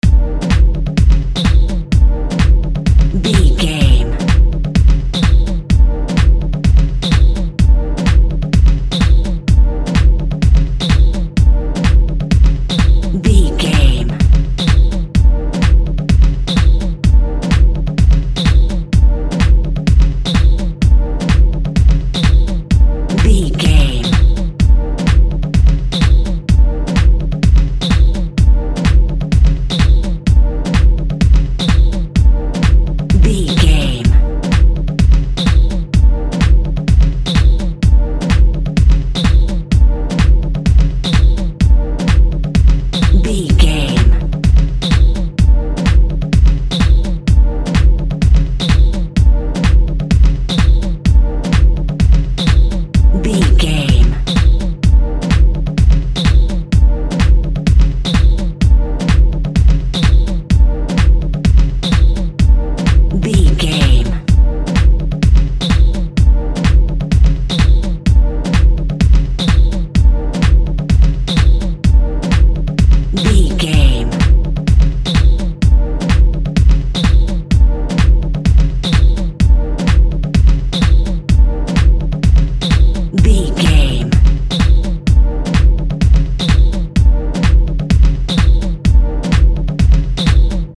Fast paced
Ionian/Major
E♭
aggressive
powerful
funky
groovy
futuristic
driving
energetic
drum machine
synthesiser
electro house
synth lead
synth bass